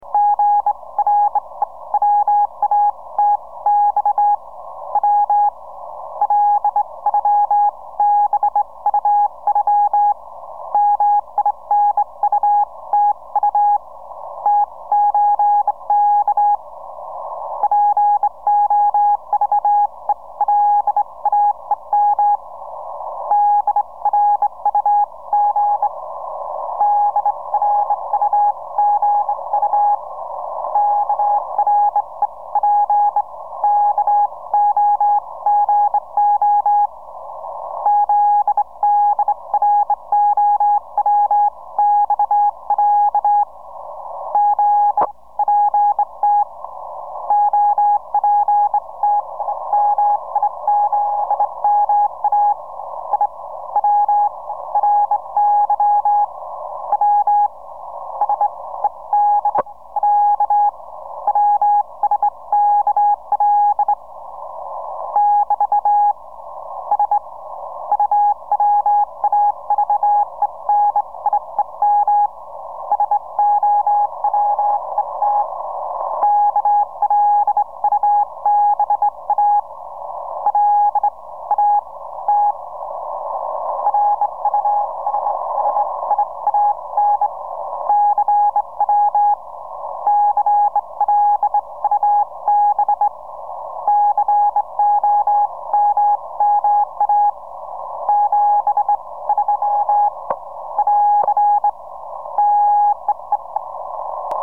Fin du message de nouvel an sur 14048 kHz